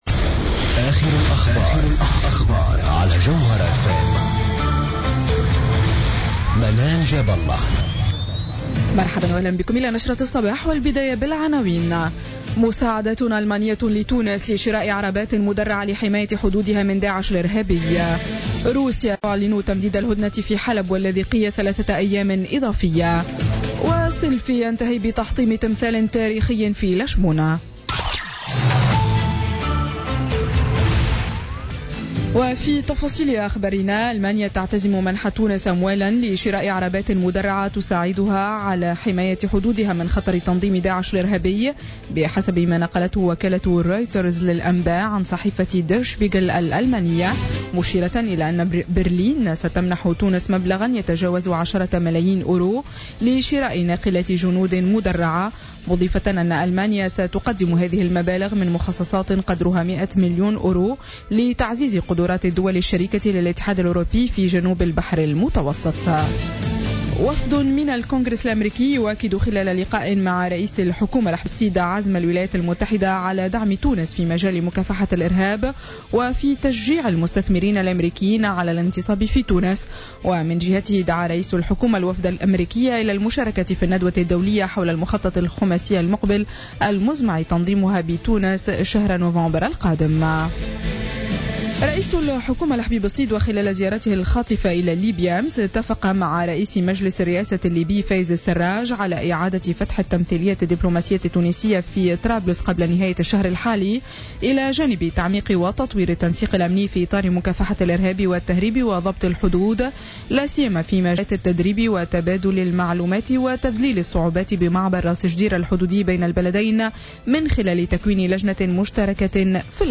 نشرة أخبار السابعة صباحا ليوم السبت 7 ماي 2016